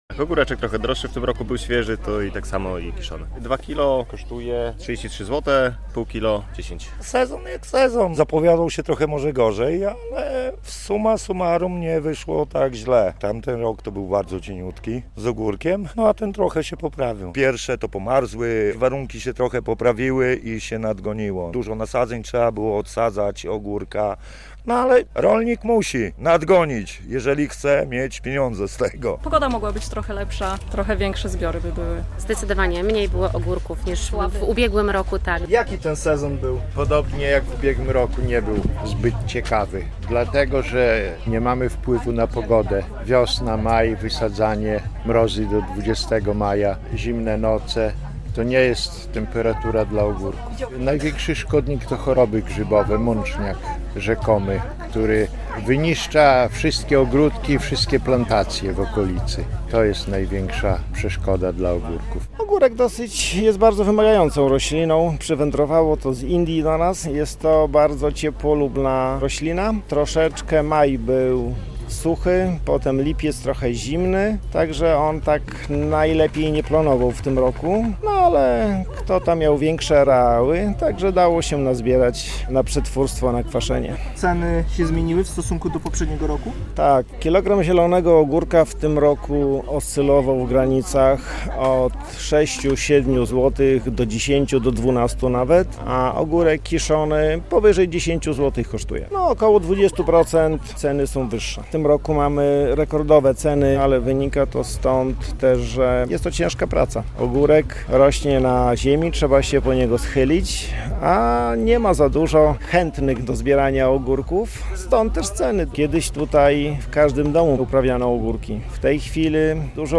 Wiadomości - Sezon ogórkowy w pełni, a ogórków jakby mniej i droższe